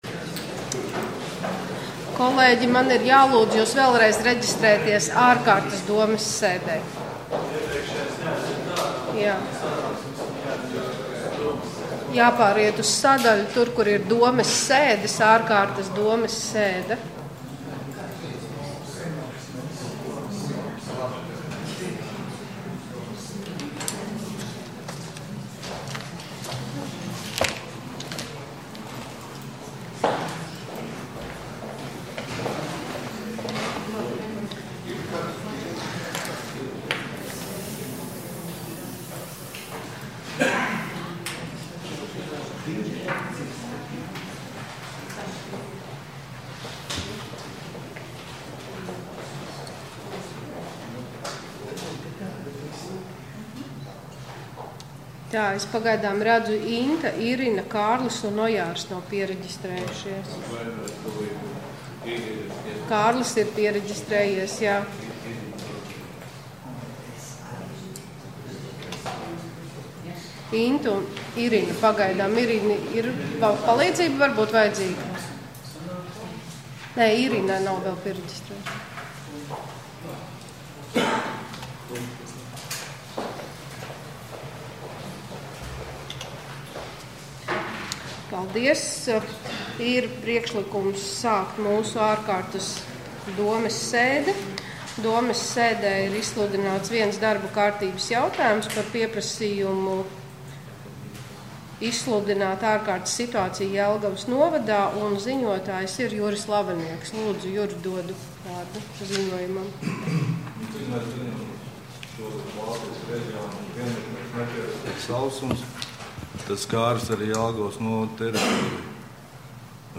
Domes ārkārtas sēde Nr. 7